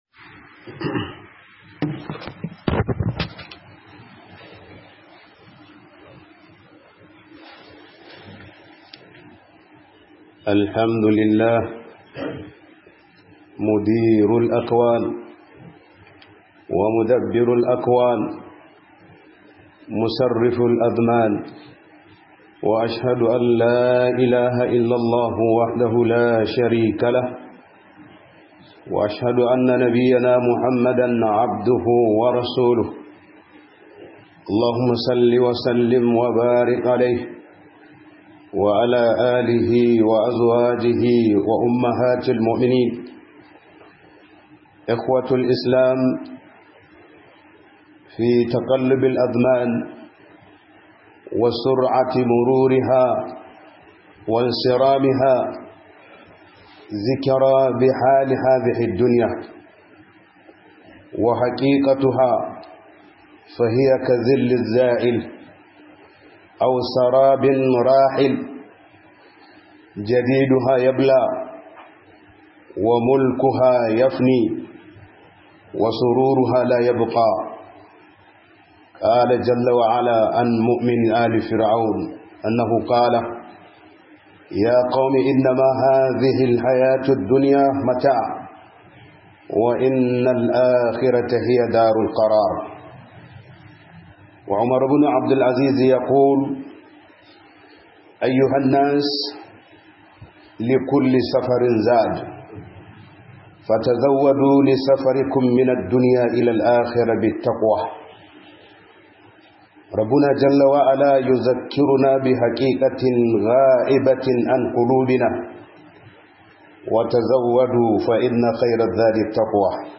Kowa Ya Lura Da Yadda Rayuwa Ke Tafiya - HUDUBA